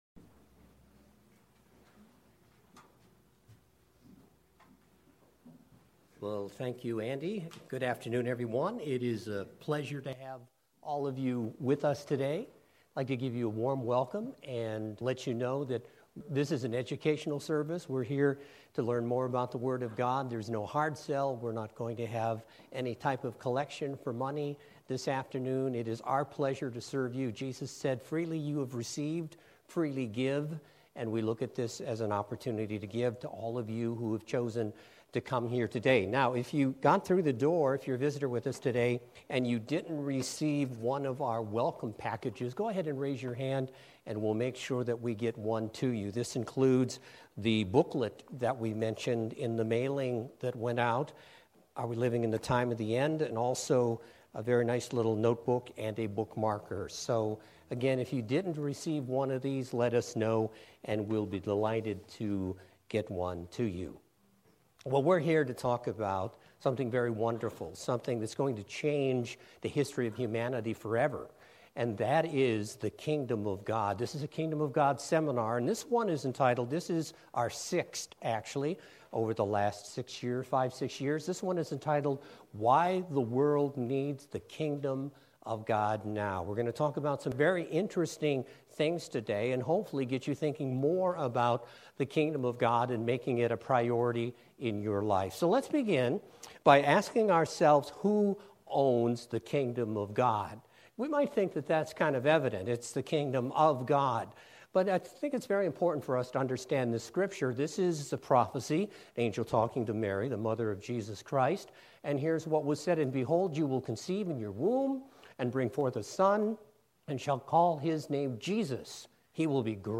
This was a local Kingdom of God seminar given in the greater Cleveland area.